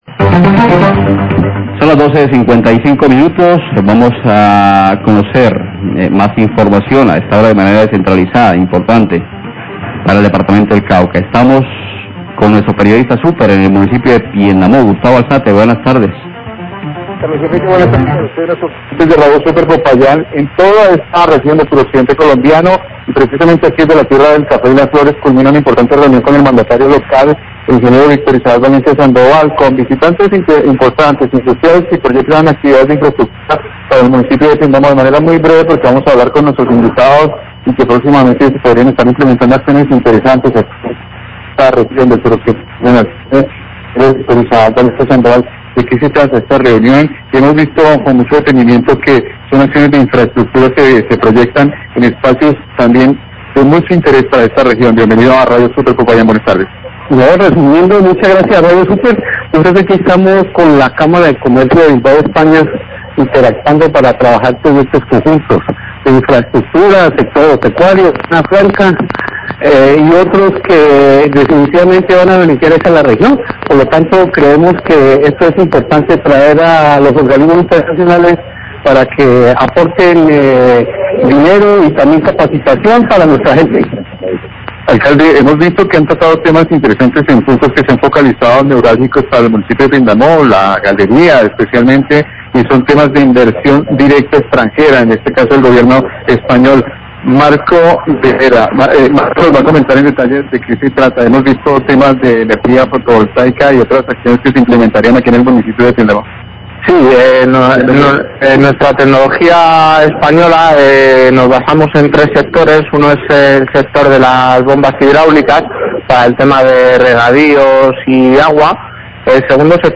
Radio
En Piendamó se realizó una reunión con funcionarios de la Cámara de Comercio de España que proyectan actividades de desarrollo y beneficio para la región como entre los que se encuentran un proyecto de  energía renovable con paneles solares y baterías. Declaraciones del Alcalde de Piendamó, Isaac Valencia y funcionario de la Cámara de Comercio de España.